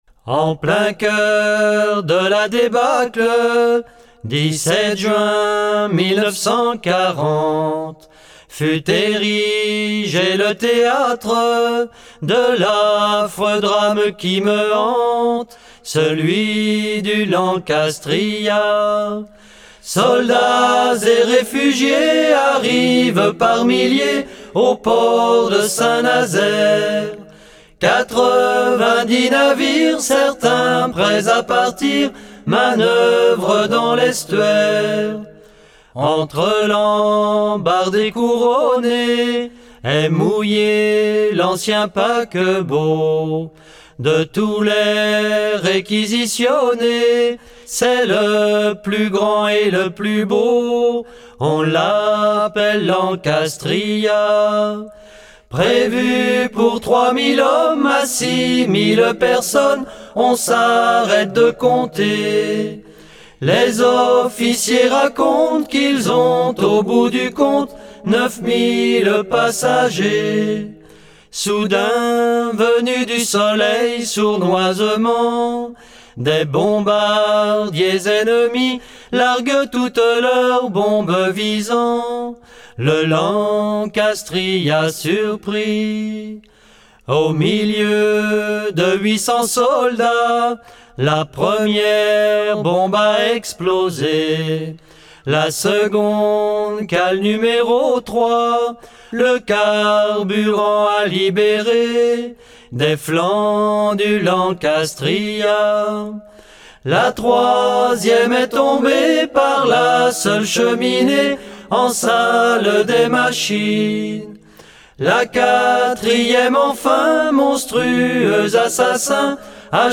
Genre strophique
Pièce musicale éditée